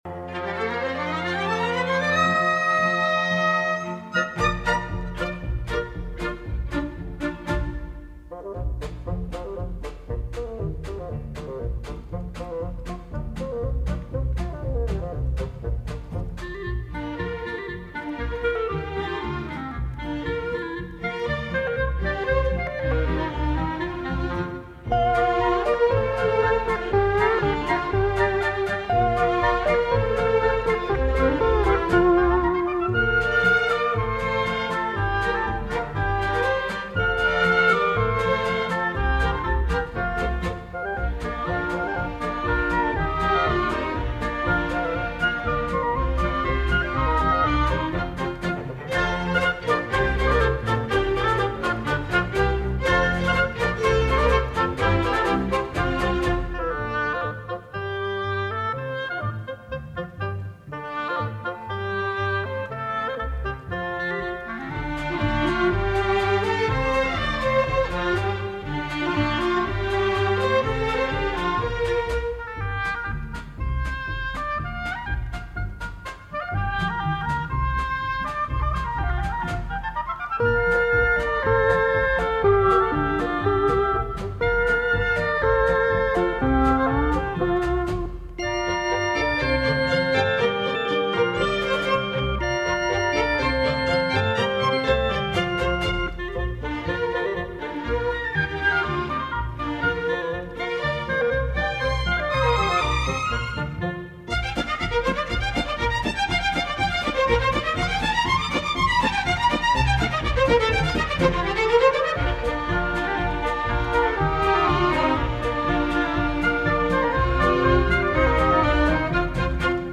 轻音乐（LIGHT MUSIC）指介于古典音乐和流行音乐之间的一种通俗音乐形式。
轻音乐一般以小型乐队加以演奏，结构简单、节奏明快、旋律优美。
旋律优美 轻松 悠闲